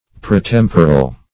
Search Result for " pretemporal" : The Collaborative International Dictionary of English v.0.48: Pretemporal \Pre*tem"po*ral\, a. (Anat.)